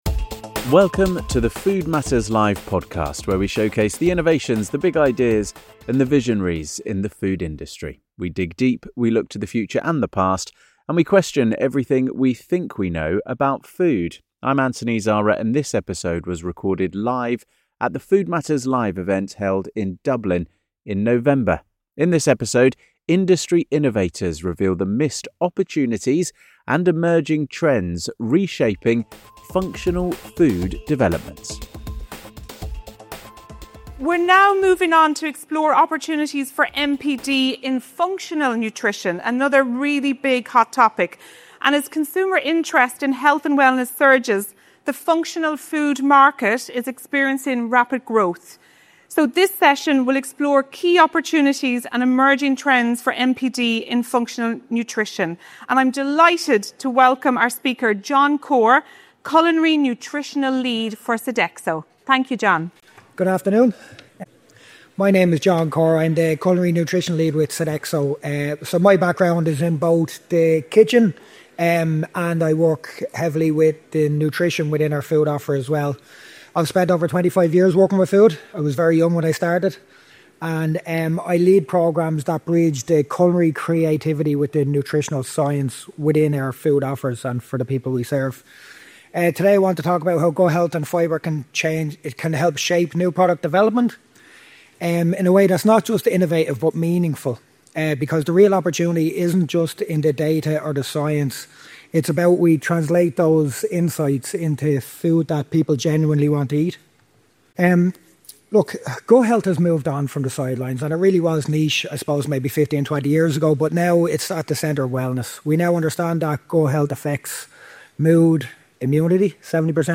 In this episode of the Food Matters Live podcast, recorded at our Dublin event, industry innovators reveal the missed opportunities and emerging trends reshaping functional food development.